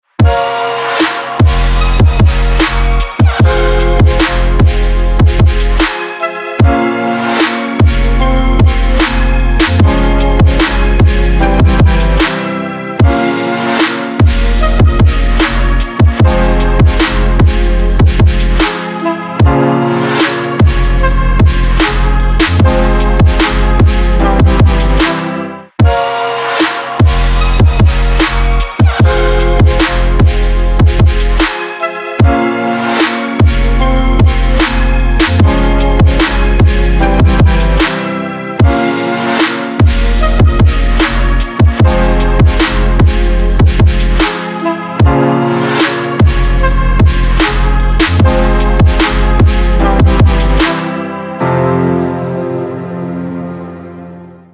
без слов
басы
club
Trance
тяжелый бас